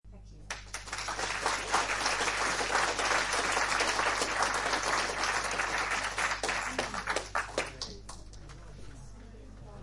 Download Press Conference sound effect for free.
Press Conference